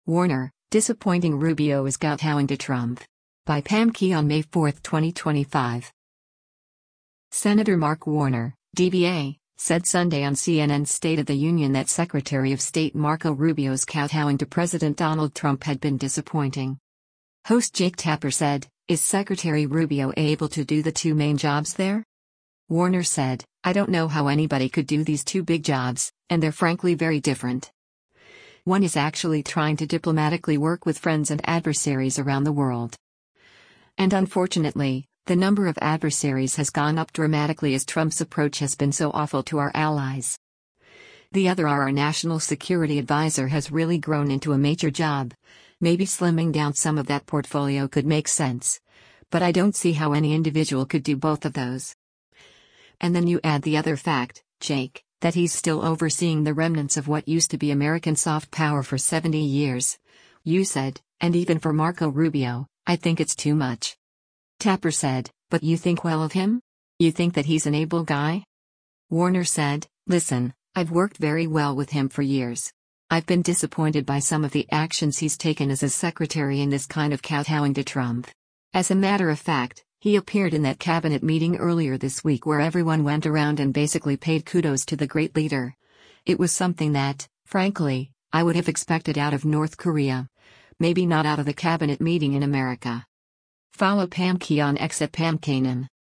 Senator Mark Warner (D-VA) said Sunday on CNN’s “State of the Union” that Secretary of State Marco Rubio’s “kowtowing” to President Donald Trump had been disappointing.